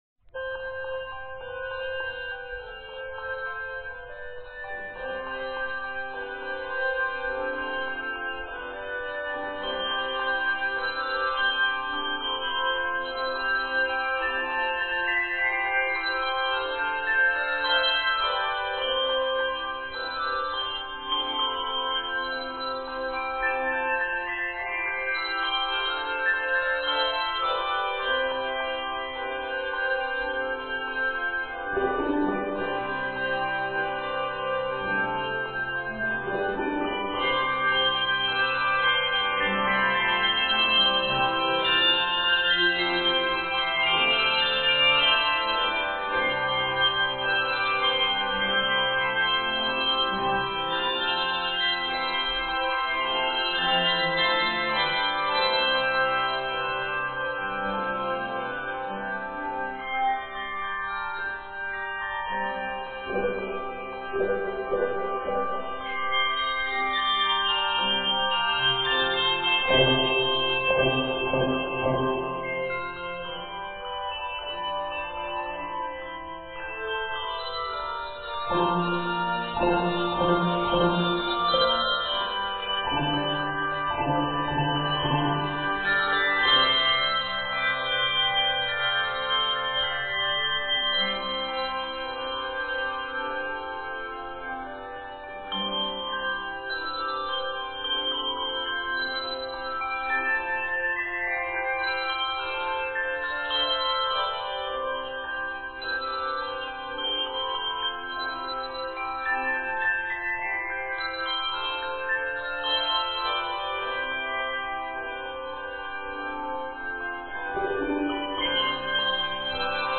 Written in C Major, measures total 68.